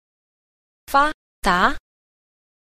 8. 發達 – fādá – phát đạt
Cách đọc: